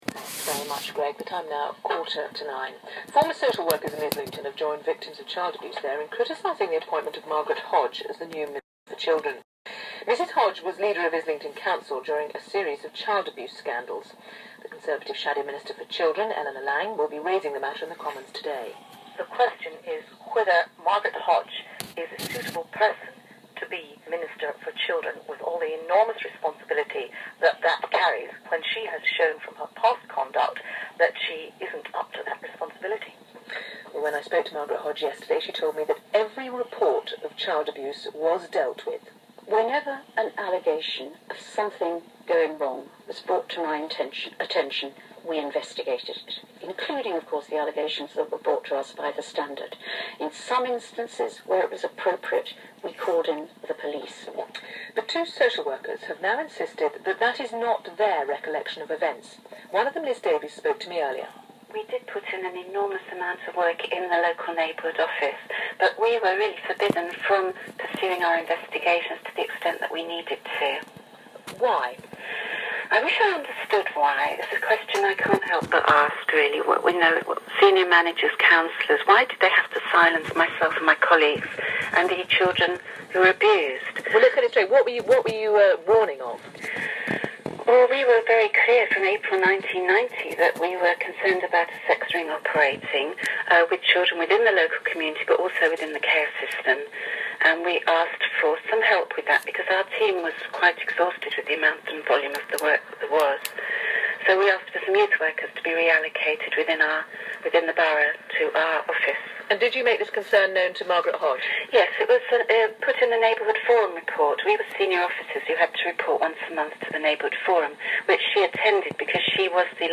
Today Programme. Interview